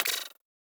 Twisted Mech Notification1.wav